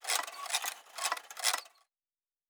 Metal Tools 08.wav